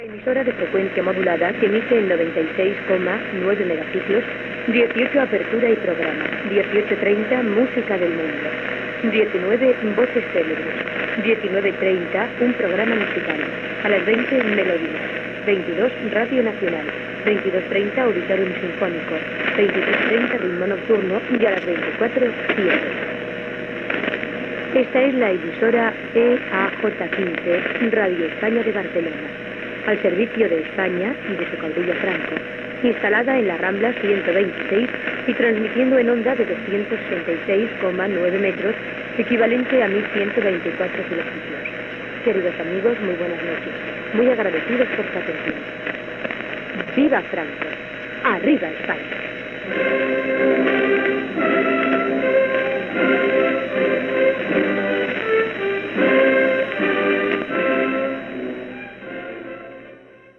Programació en FM de l'endemà i tancament d'emissió amb l'himne espanyol.